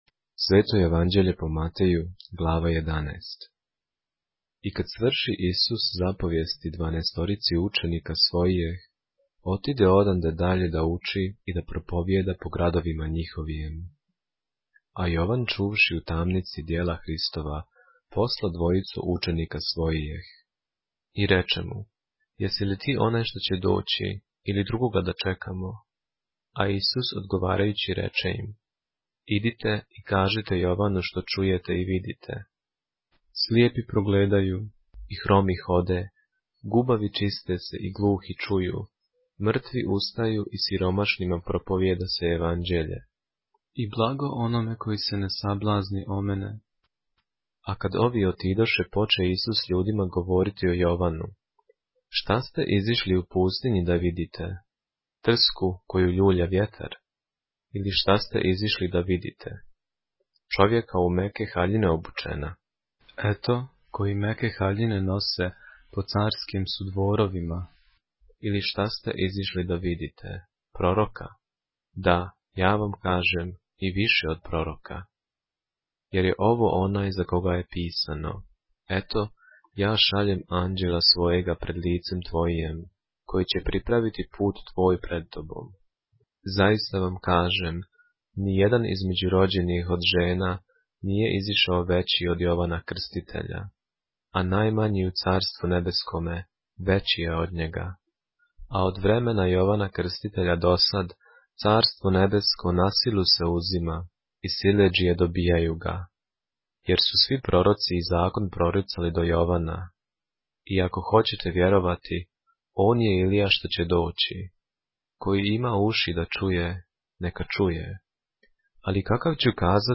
поглавље српске Библије - са аудио нарације - Matthew, chapter 11 of the Holy Bible in the Serbian language